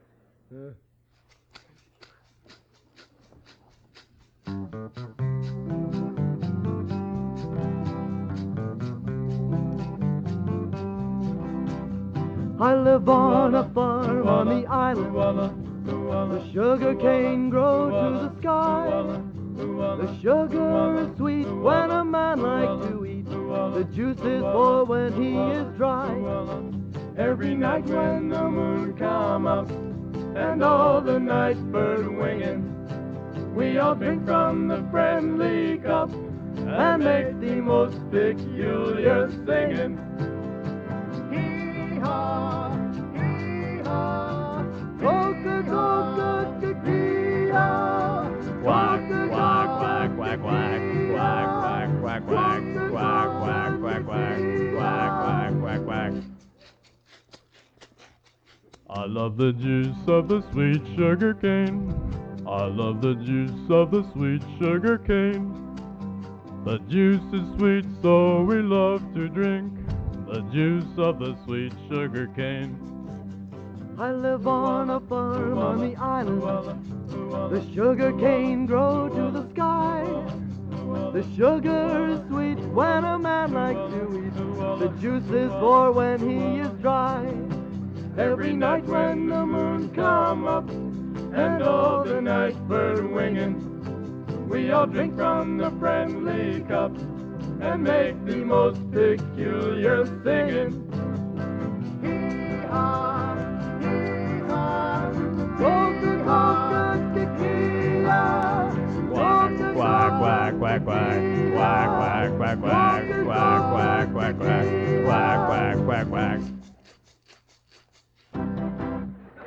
freshman quartet